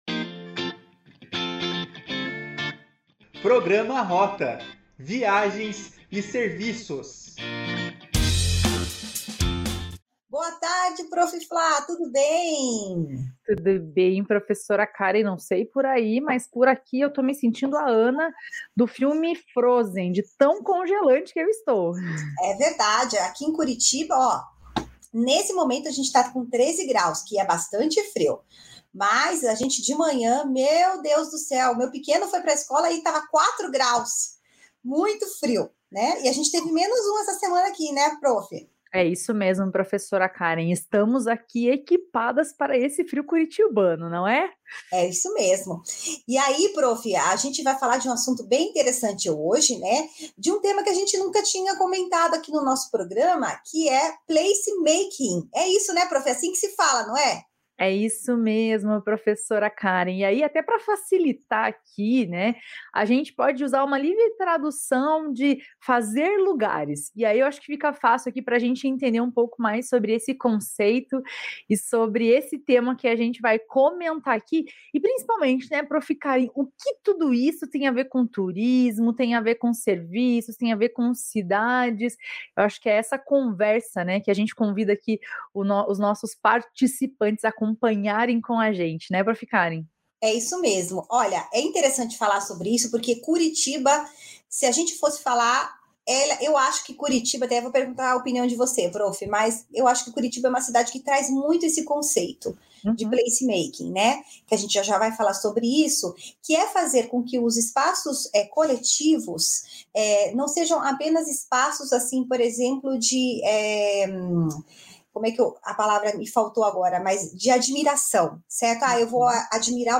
A conversa